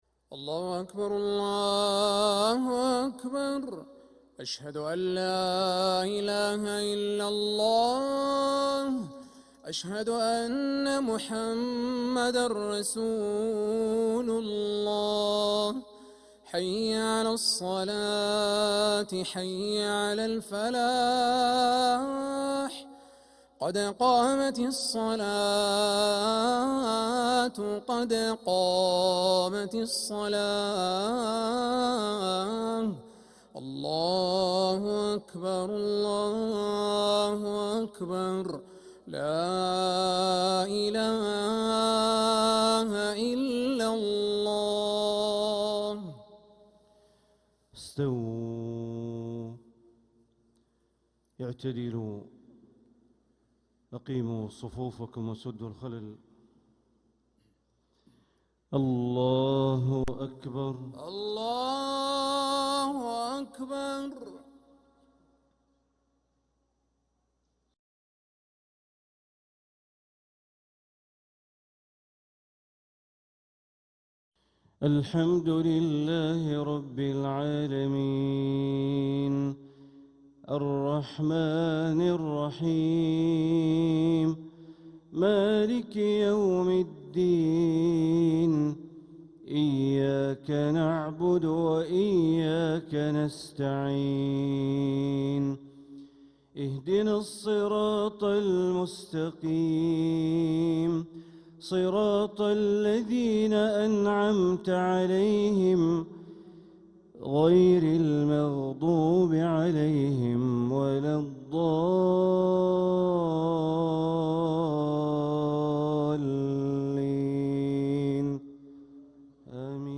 Makkah Fajr - 19th April 2026